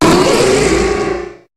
Cri de Méga-Pharamp dans Pokémon HOME.
Cri_0181_Méga_HOME.ogg